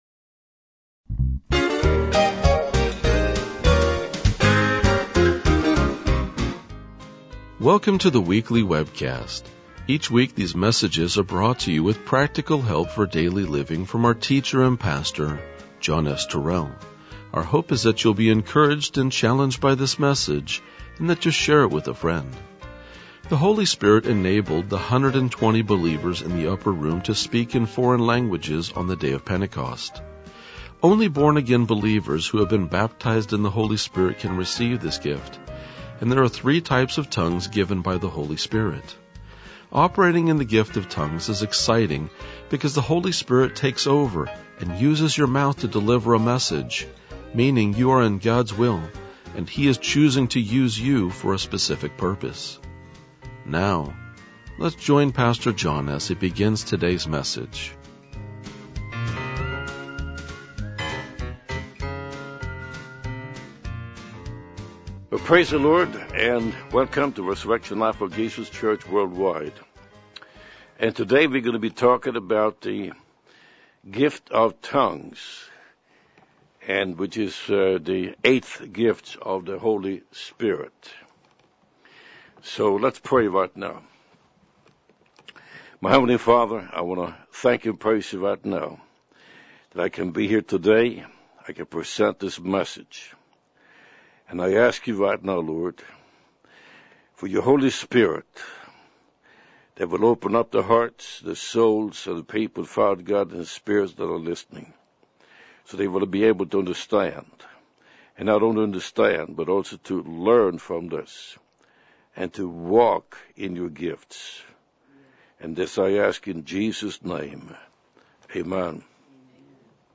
RLJ-2029-Sermon.mp3